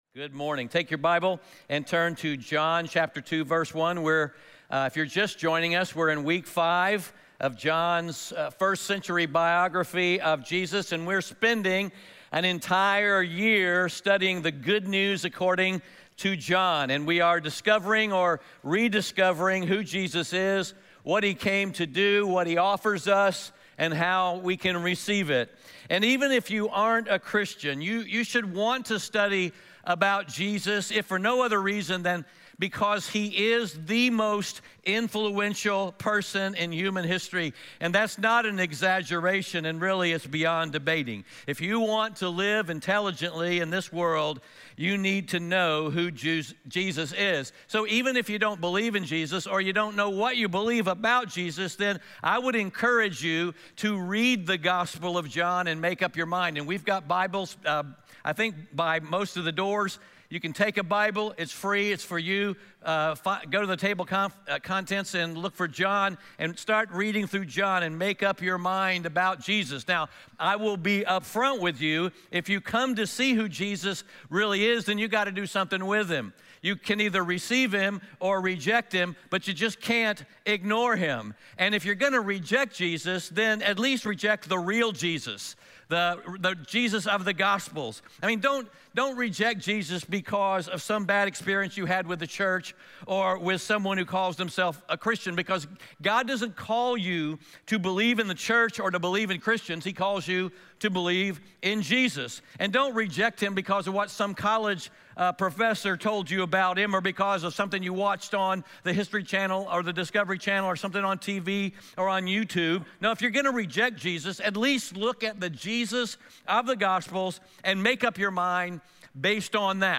John 2:1-12 Audio Sermon Notes (PDF) Ask a Question Scripture: John 2:1-12 There are many false notions about Christianity and it’s sad to say that many people are rejecting it for the wrong reasons.